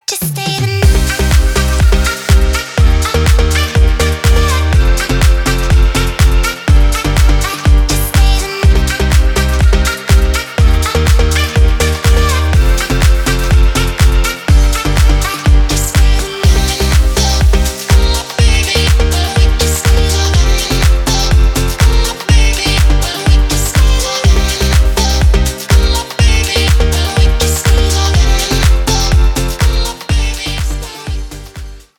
клубные
ремиксы